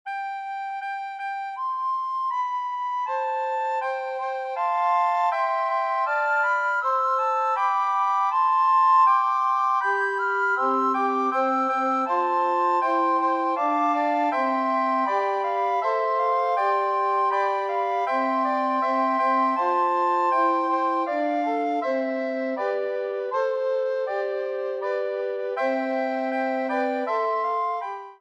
S A T B A fairly straight-forward miniature in four parts